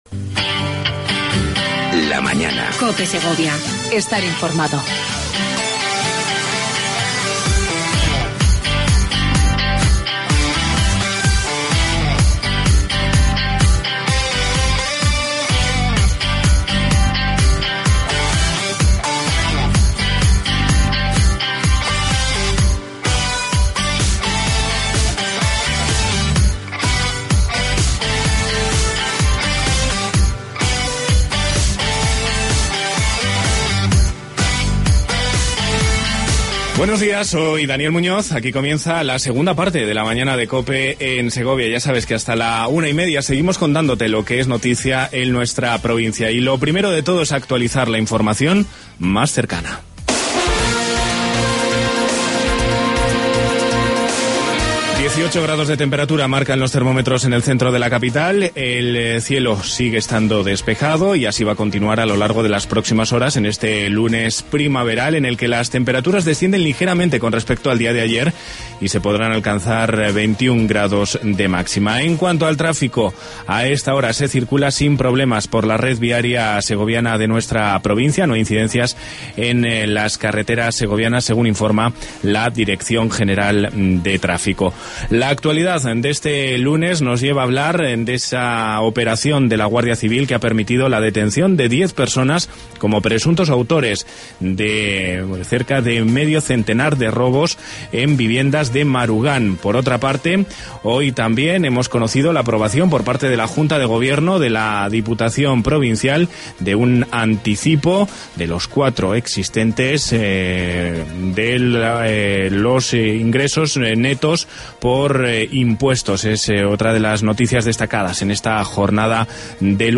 AUDIO: Entrevista con Javier López Escobar, Delegado Territorial de La Junta de Castailla y León en Segovia.